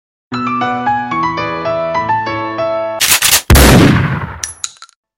звук выстрела